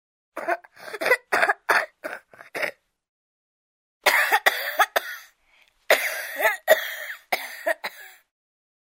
Кашель ребенка